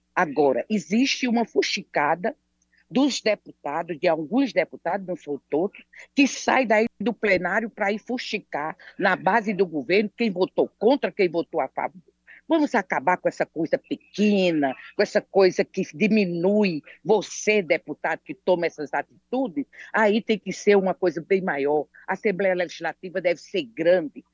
Durante um debate sobre Vetos do Governo do Estado na Assembleia Legislativa da Paraíba (ALPB), a deputada Dra. Paula (PP) criticou alguns colegas governistas que iriam fazer fuxico na base a respeito de deputados que votam contra ou favor aos vetos do governador João Azevêdo (PSB) a projetos de lei aprovados na Casa.